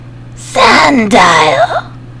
infinitefusion-e18/Audio/SE/Cries/SANDILE.mp3 at releases-April